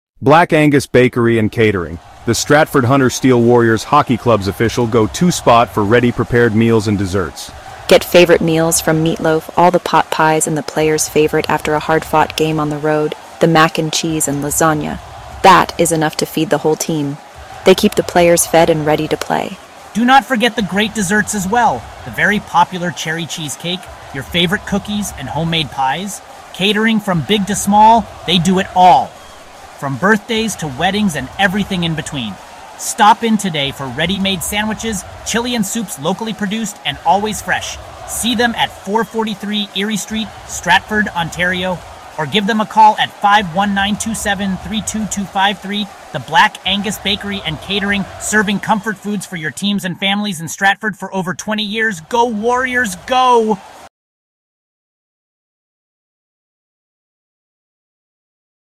2025-Black-Angus-Bakery-Local-Ad-Stratford-Warriors-Hockey-Season.mp3